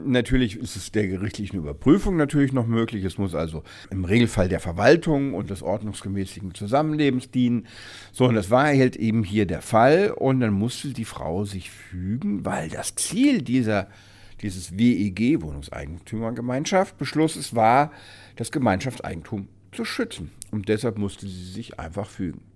Download O-Ton